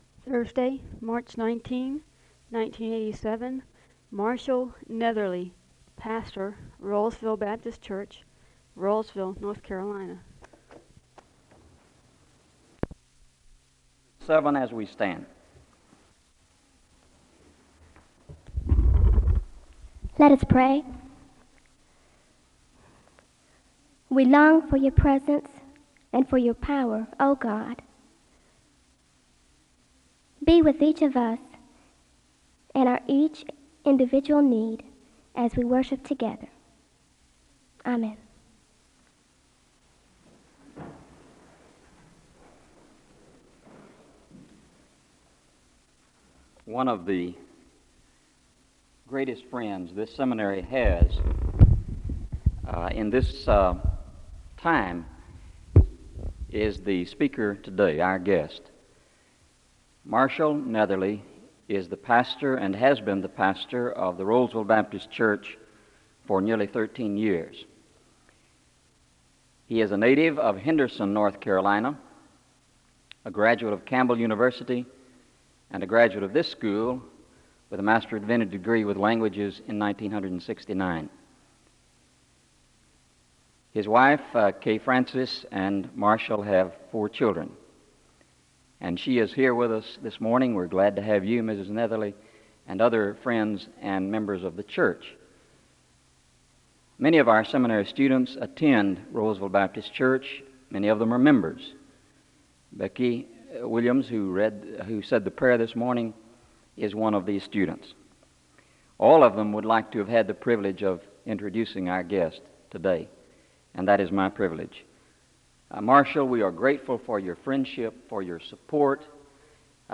The service begins with a moment of prayer (0:00-0:43).
The choir sings a song of worship (4:11-7:58).
SEBTS Chapel and Special Event Recordings SEBTS Chapel and Special Event Recordings